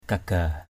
/ka-ɡ͡ɣa:/ (cv.) guga g~g% /ɡ͡ɣu-ɡ͡ɣa:/